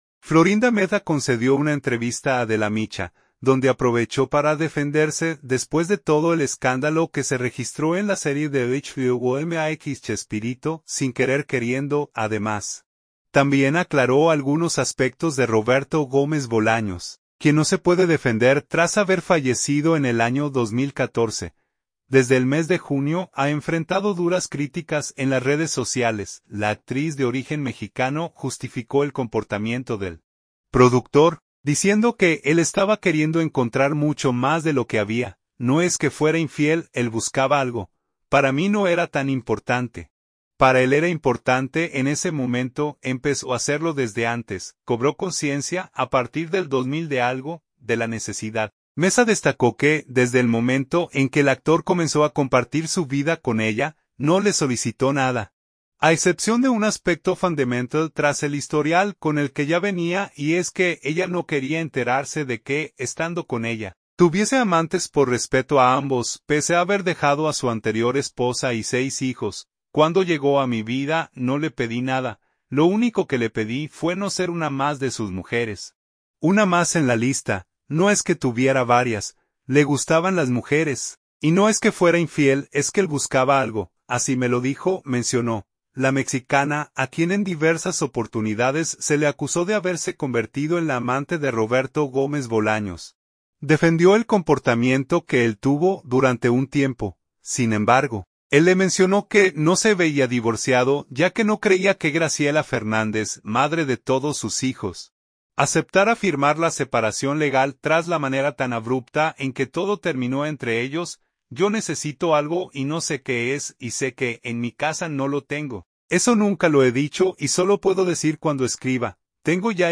Florinda Meza concedió una entrevista a Adela Micha, donde aprovechó para defenderse después de todo el escándalo que se registró en la serie de HBO MAX “Chespirito: Sin querer queriendo”.